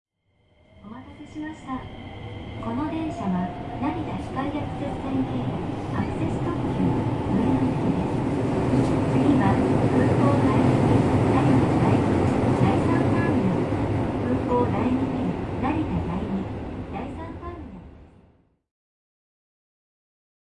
描述：2011年1月在日本东京成田机场录制的。即将登上香港航空公司飞往香港的航班。用Zoom H2在2声道环绕模式下录制，没有挡风玻璃。文件没有经过修改。
标签： 机场 公告 登机 现场录音 日本 成田 东京
声道立体声